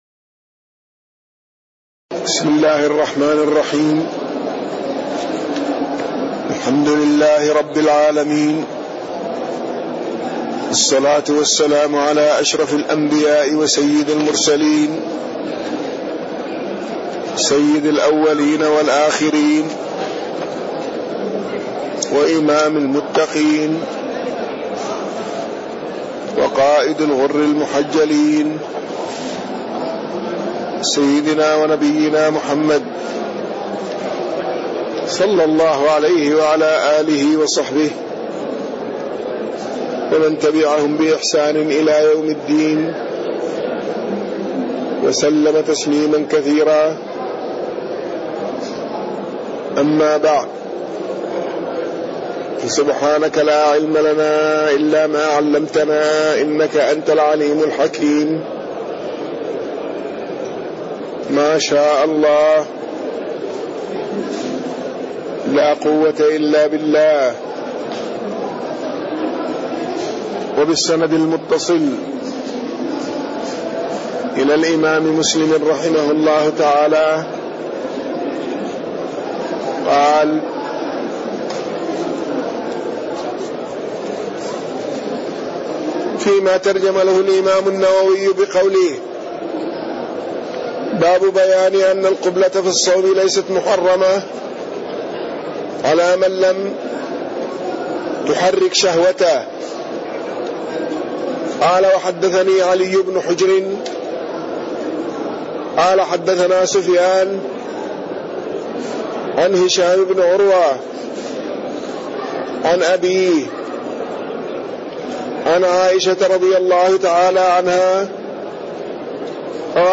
تاريخ النشر ١٦ محرم ١٤٣٣ هـ المكان: المسجد النبوي الشيخ